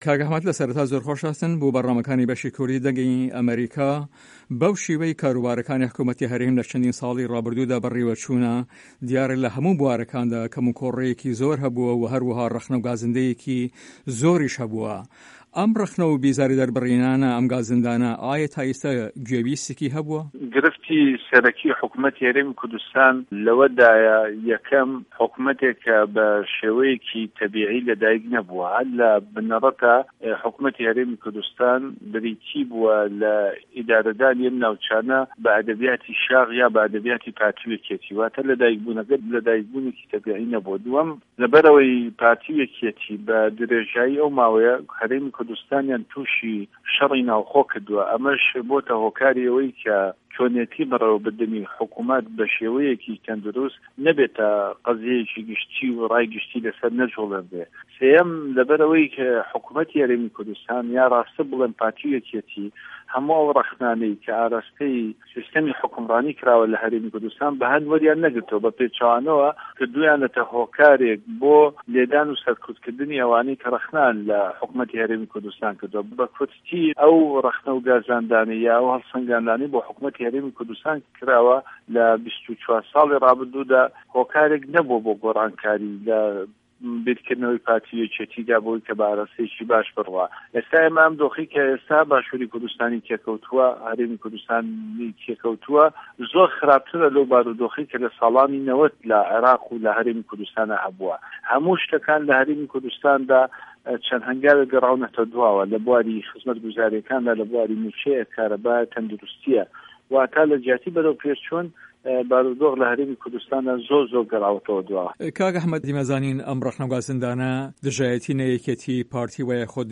هه‌رێمه‌ کوردیـیه‌کان - گفتوگۆکان
له‌ هه‌ڤپه‌ێڤینێکدا له‌گه‌ڵ به‌شی کوردی ده‌نگی ئه‌مه‌ریکا